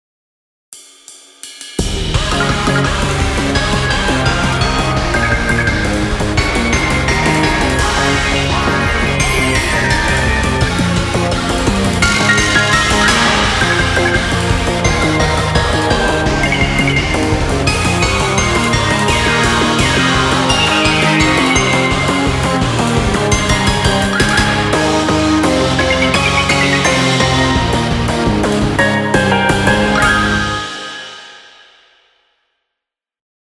どんちゃんわいわい、無法地帯。
inst